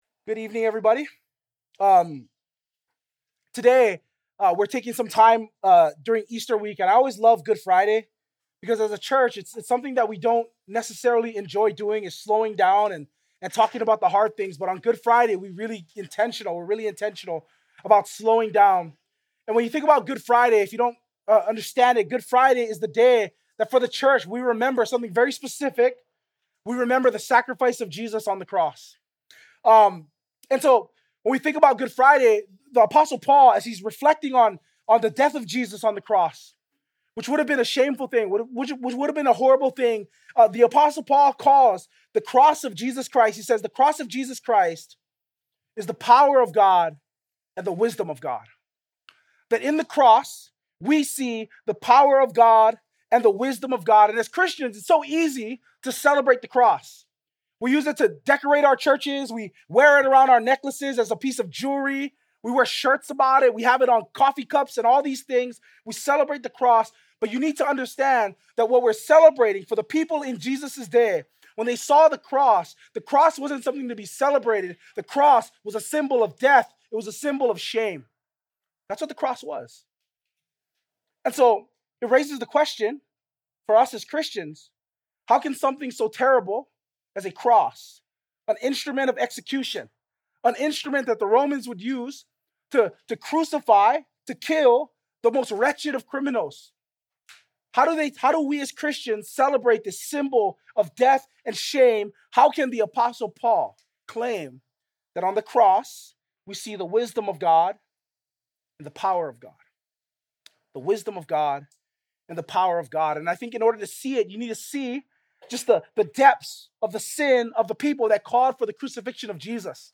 Good Friday